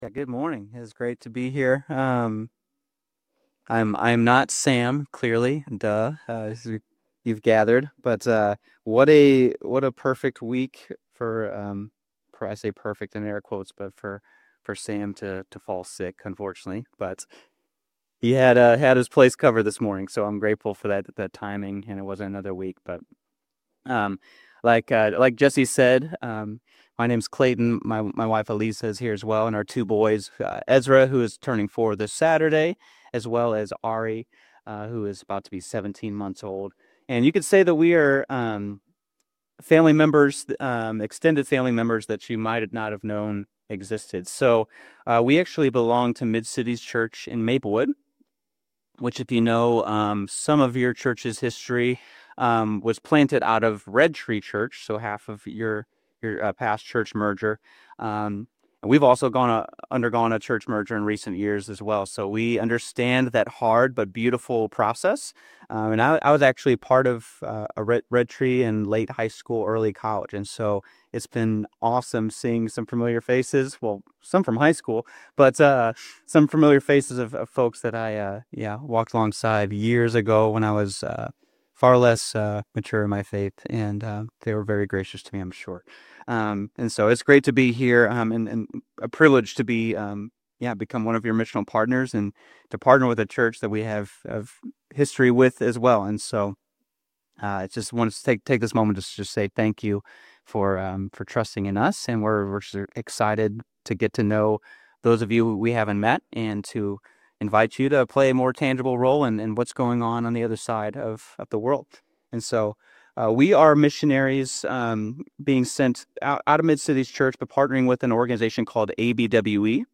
Humble Royals - Stand Alone Sermon